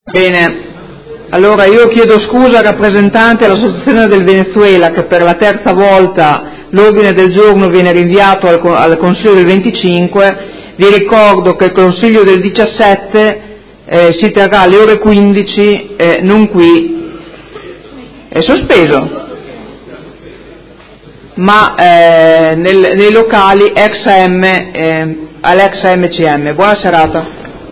Presidentessa — Sito Audio Consiglio Comunale
Seduta dell'11/01/2018. Sospensione Ordine del Giorno presentato dal Movimento cinque Stelle avente per oggetto: Maggior coinvolgimento del Consiglio Comunale nel percorso delle nomine.